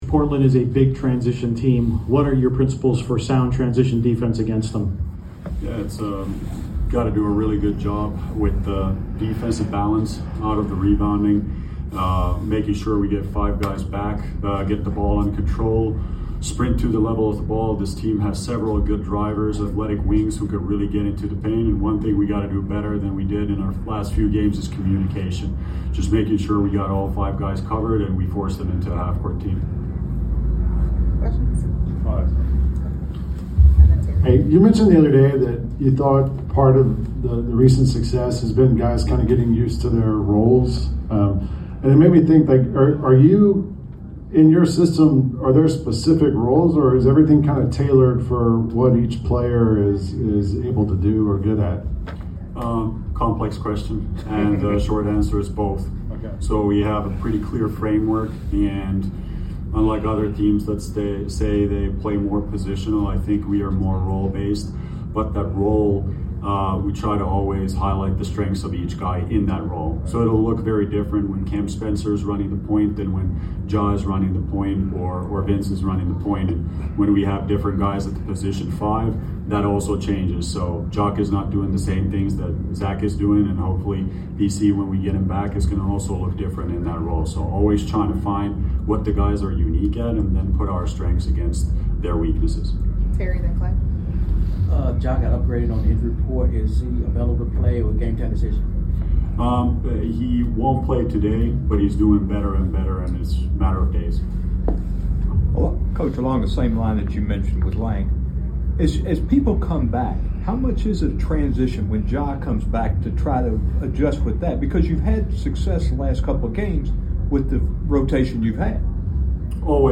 Memphis Grizzlies Coach Tuomas Iisalo Pregame Interview before taking on the Portland Trail Blazers at FedExForum.